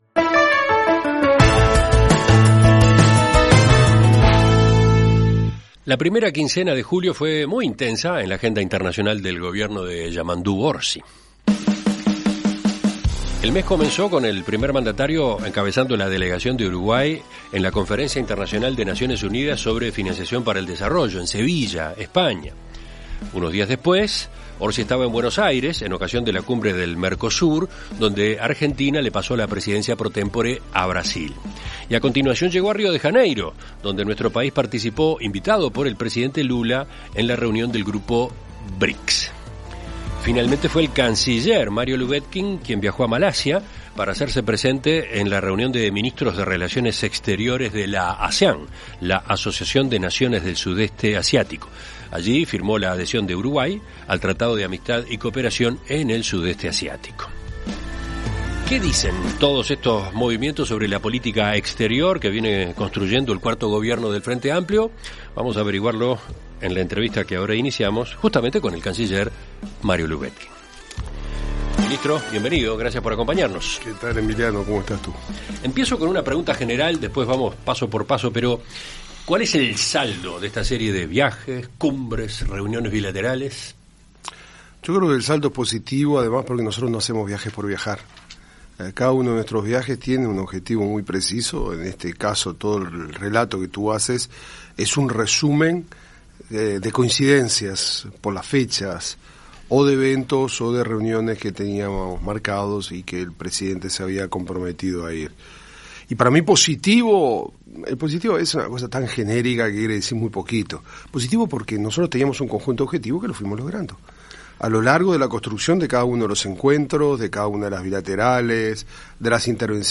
En Perspectiva Zona 1 – Entrevista Central: Mario Lubetkin - Océano
Conversamos con Mario Lubetkin.